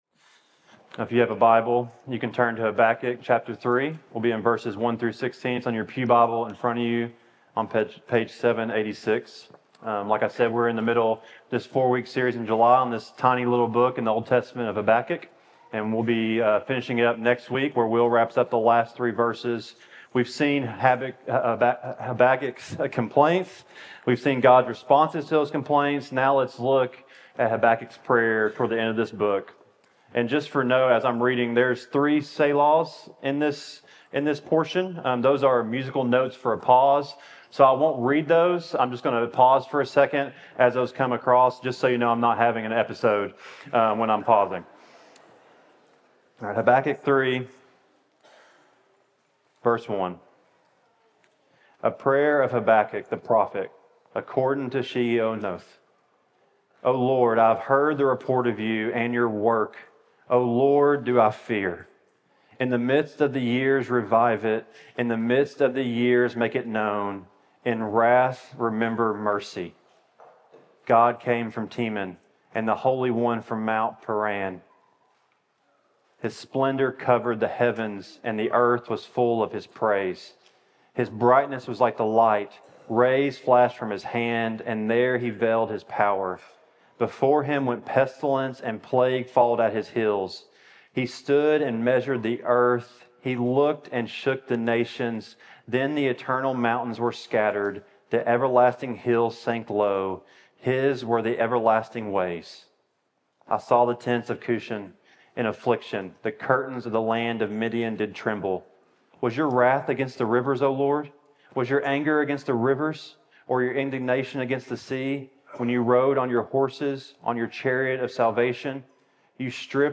Facing Our Fears TCPC Sermon Audio podcast